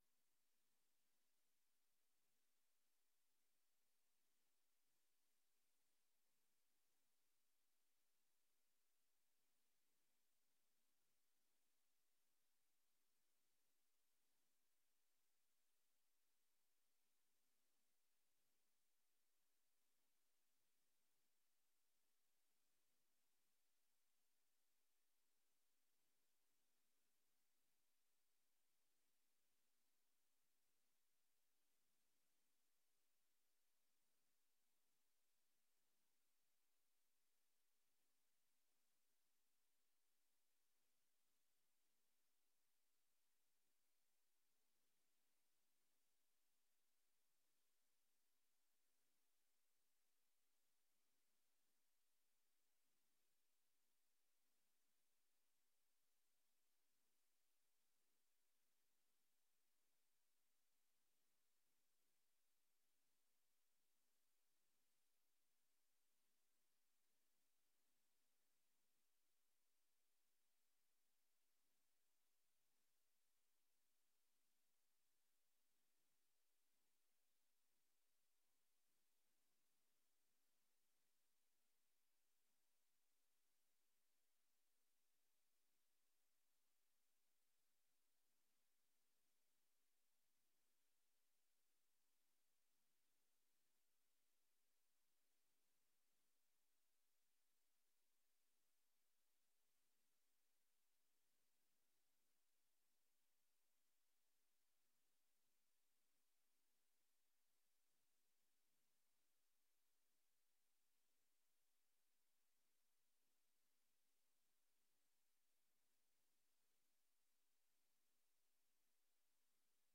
Raadsvergadering 20 mei 2025 19:30:00, Gemeente Dronten
Toespraak van:- Herbenoemingstoespraak door burgemeester de heer J.P. Gebben
Locatie: Raadzaal